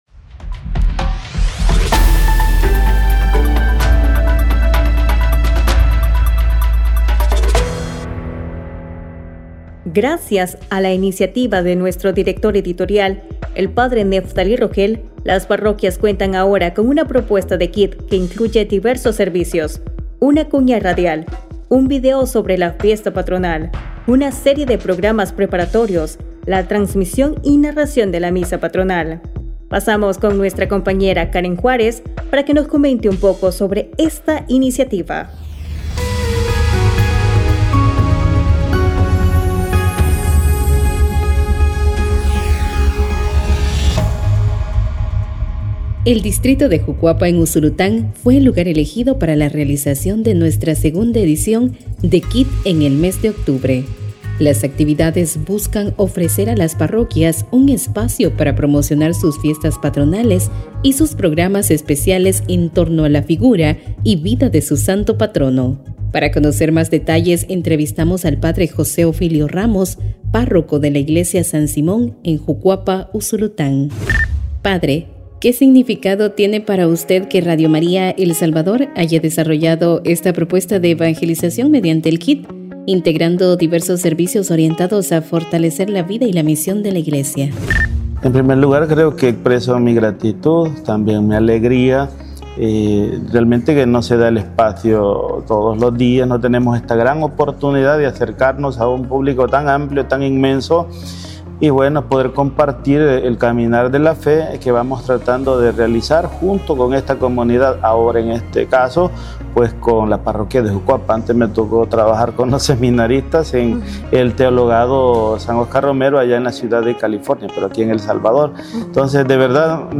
ENTREVISTA-JUCUAPA-USULUTAN.mp3